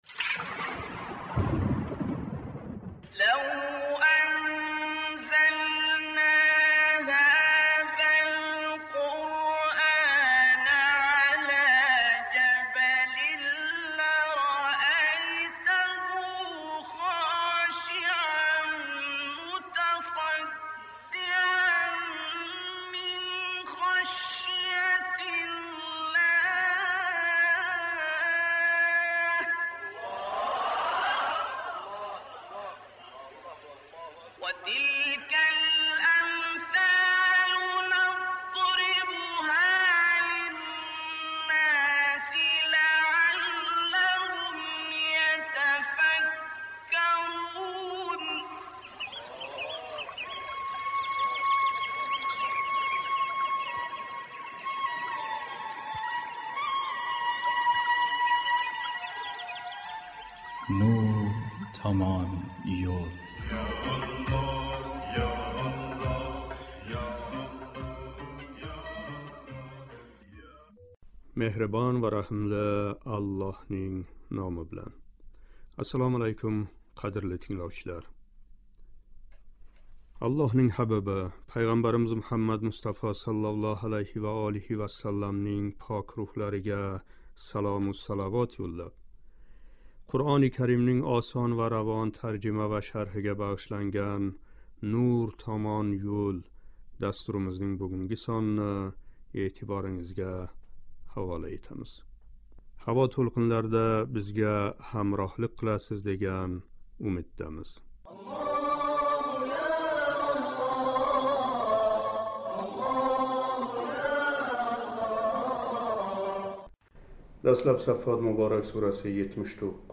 « Саффот " муборак сураси 79-92-ояти карималарининг шарҳи. Дастлаб “Саффот” муборак сураси 79-82- ояти карималарининг тиловатига қулоқ тутамиз: